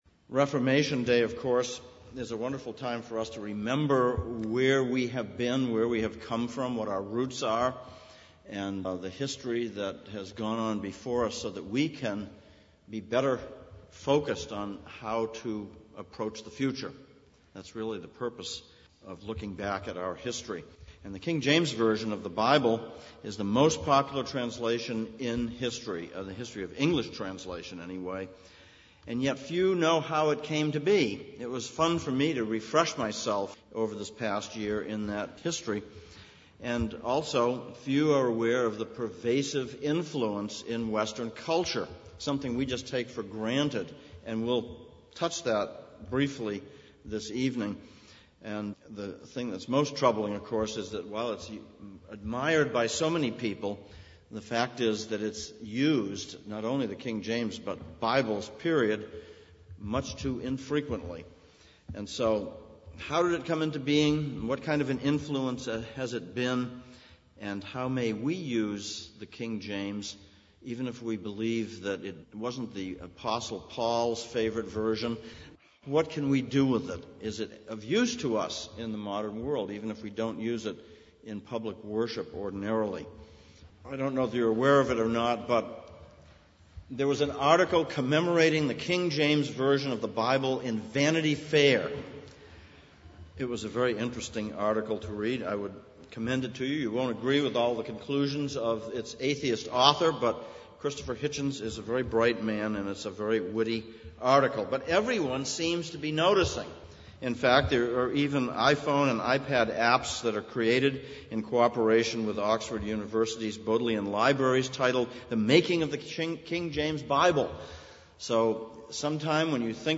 Series: Reformation Day Lectures